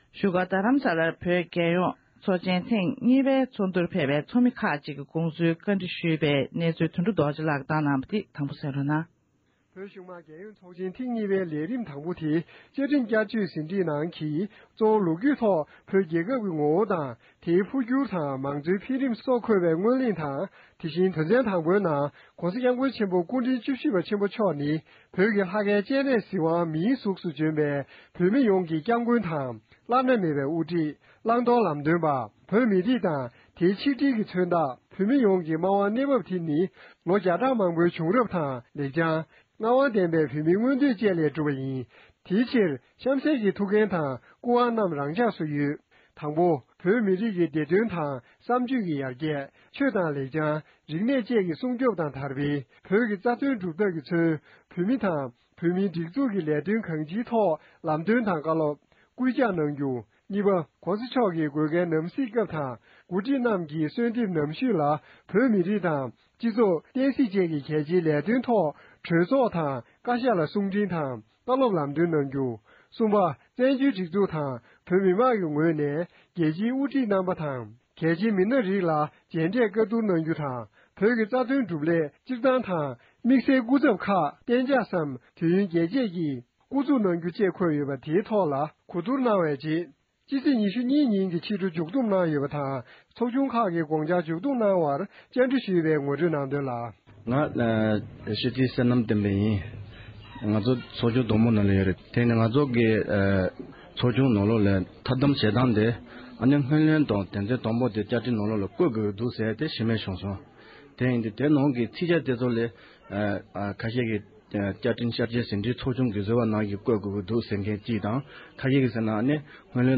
བོད་གཞུང་མང་རྒྱལ་ཡོངས་ཚོགས་ཆེན་ཐེངས་གཉིས་པའི་ཚོགས་བཅར་བའི་ལྷན་གླེང་མོལ་གནང་བ།